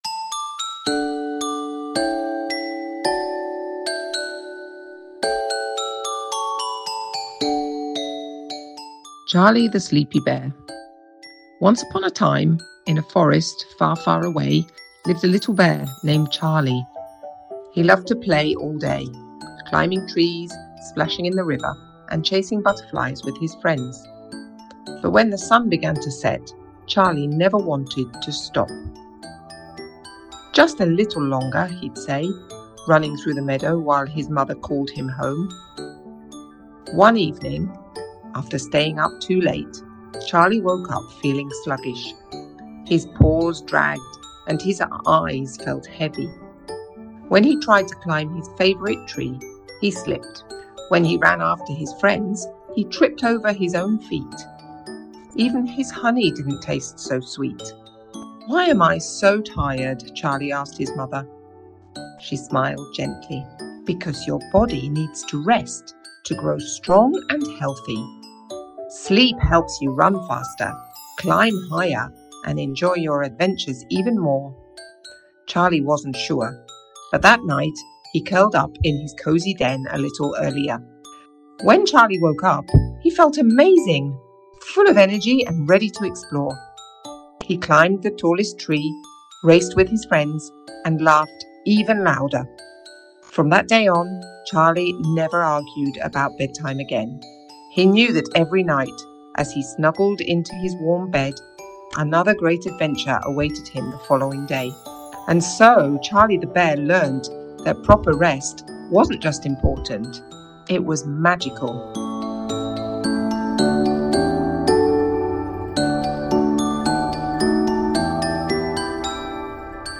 Charlie the Sleepy Bear - Una storia della buonanotte